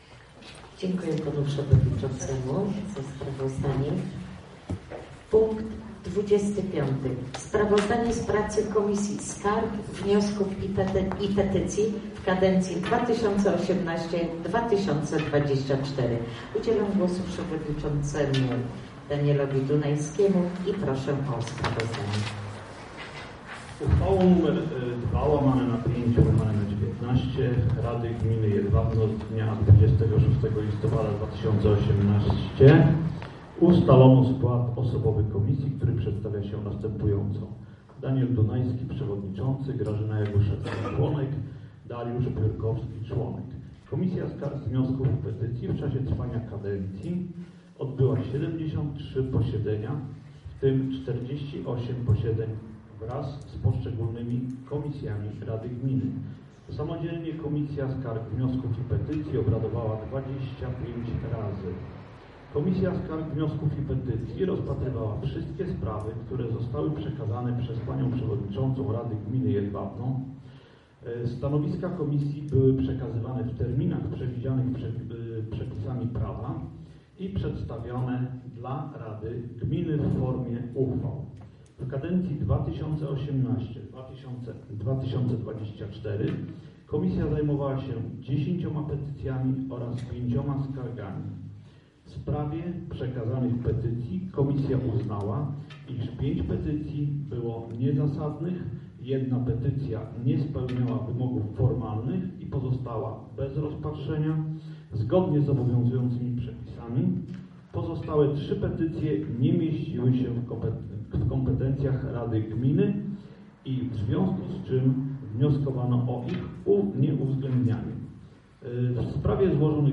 Nagrania audio z sesji Rady Gminy Jedwabno kadencja VIII 2018-2023